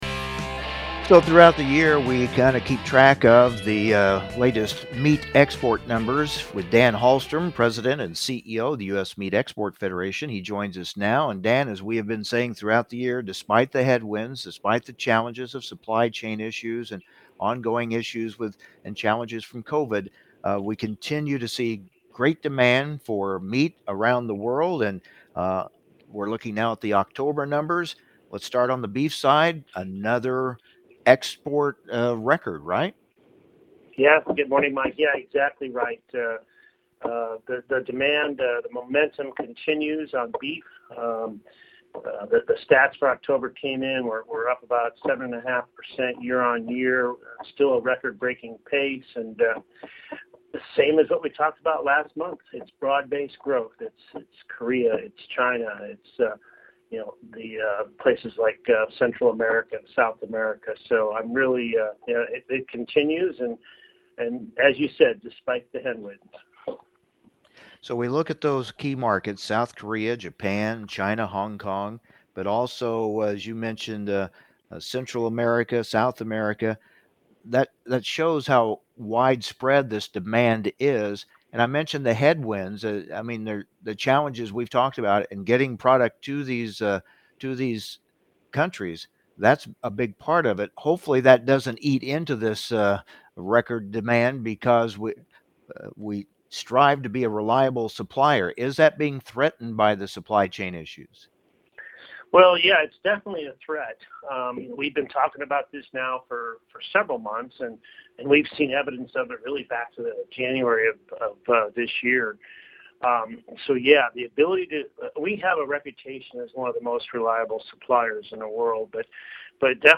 for a discussion of the latest red meat export results, including the new annual value record for U.S. beef exports. They also discuss the need to address transportation delays and other supply chain challenges.